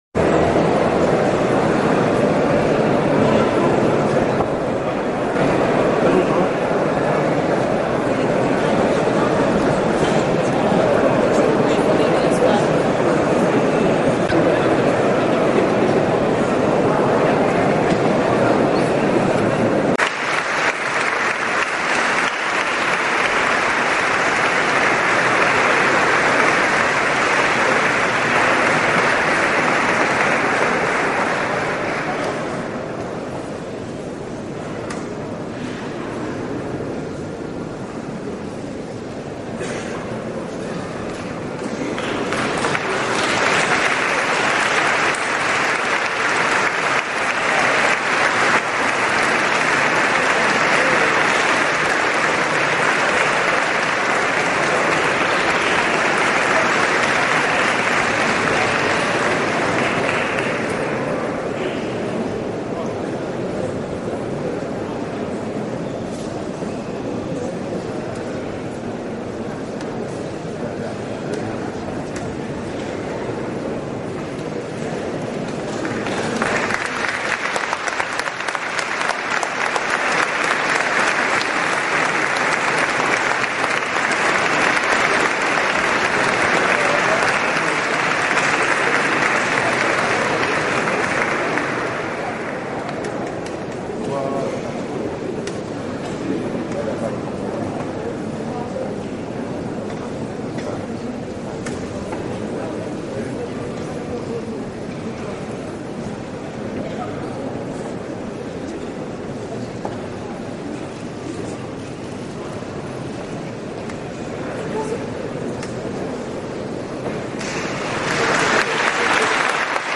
Guarda il video Celebrazione 6' 32'' 883 Scarica mp3